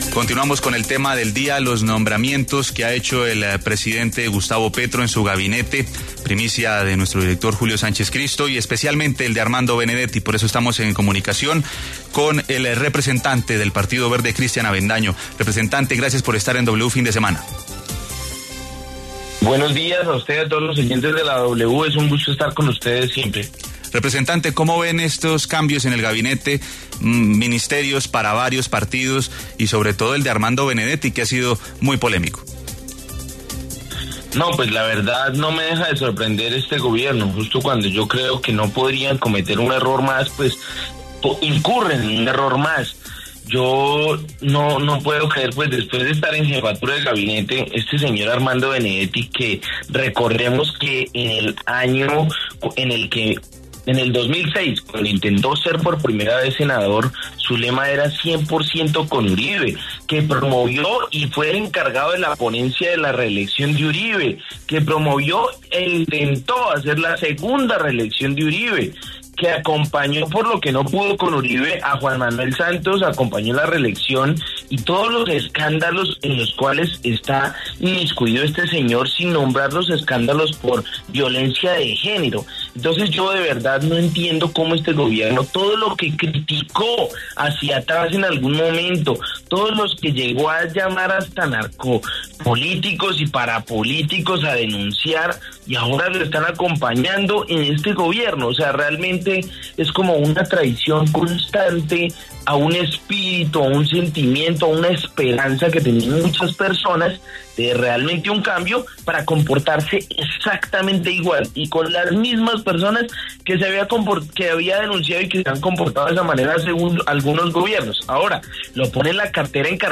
W Fin de Semana conversó con congresistas del Partido de La U, el Partido Alianza Verde y el Partido Liberal para conocer su postura frente a los nuevos movimientos en el Gobierno Petro.
José Antonio Correa, senador del partido de La U, habla en W Fin de Semana